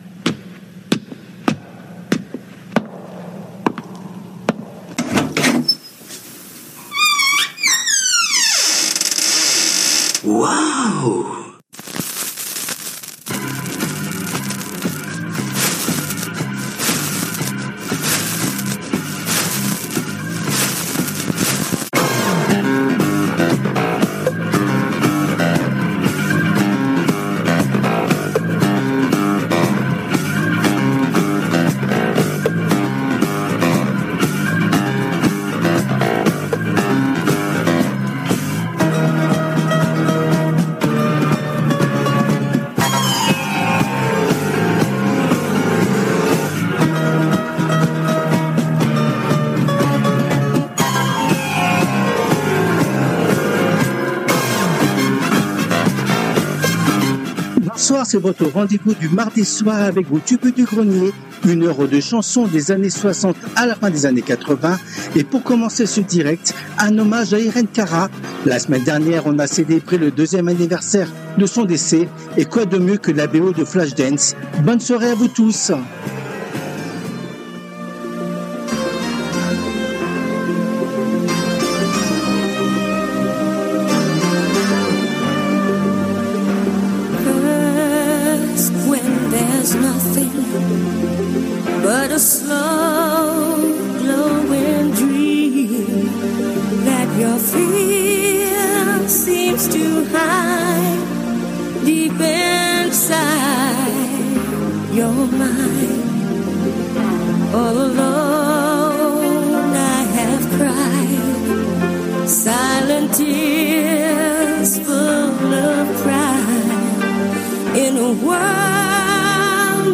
Des tubes et des découvertes des 60's 70's ou 80's
depuis les studios de RADIO RV+ à PARIS
Les Tubes connus ou oubliés des 60's, 70's et 80's